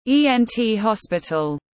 Bệnh viện tai mũi họng tiếng anh gọi là ENT hospital, phiên âm tiếng anh đọc là /ˌiː.enˈtiː hɒs.pɪ.təl/.
ENT hospital /ˌiː.enˈtiː hɒs.pɪ.təl/
E-N-T-hospital.mp3